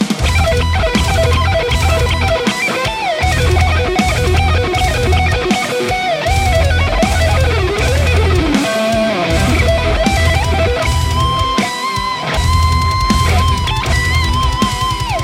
With some increadable lead tones and dark heavy rhythm tones.
Lead Mix
RAW AUDIO CLIPS ONLY, NO POST-PROCESSING EFFECTS
Hi-Gain